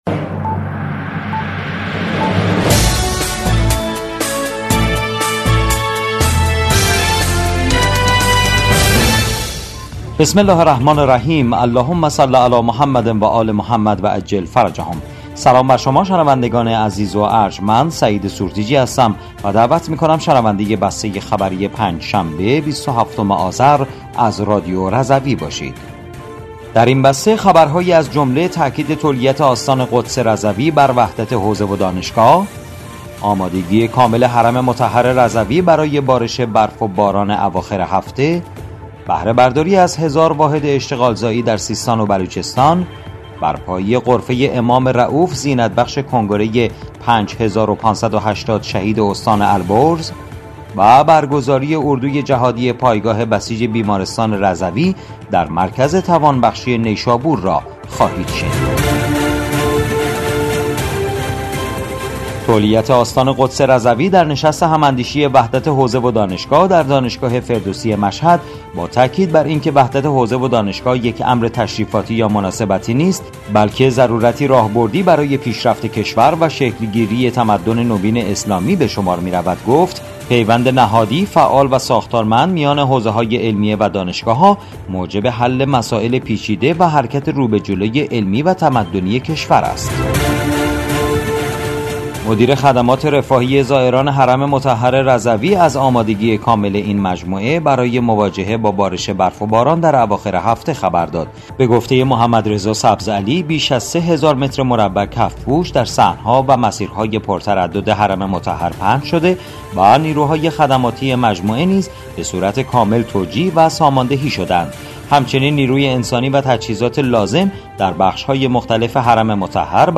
بسته خبری ۲۷ آذر ۱۴۰۴ رادیو رضوی؛